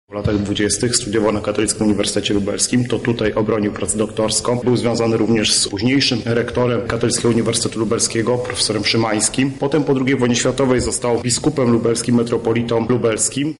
Kardynał Wyszyński jest związany z Lublinem od 20-lecia międzywojennego – mówi radny PiS Robert Derewenda: